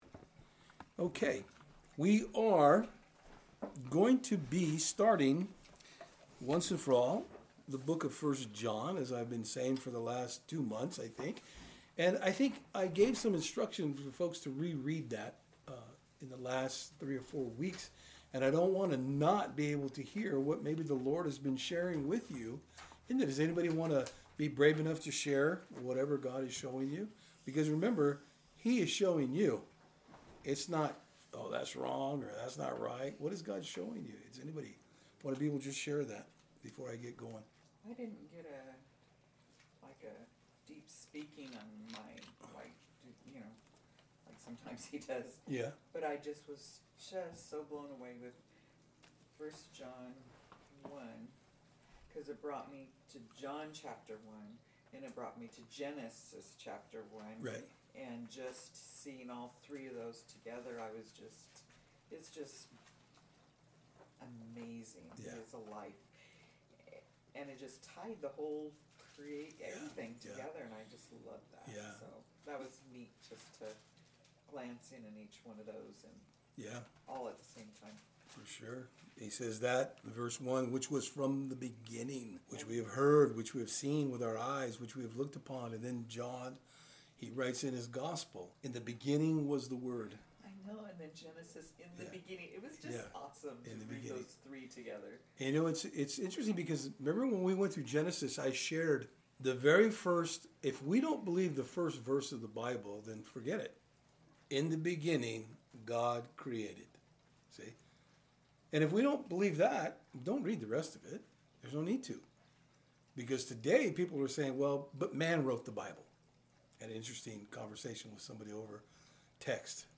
1 John 1 Service Type: Thursday Eveing Studies We are beginning the study of the Epistle of John where he warns the believers of Gnostic teachings.